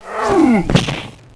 Heroes3_-_Griffin_-_DeathSound.ogg